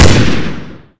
weapons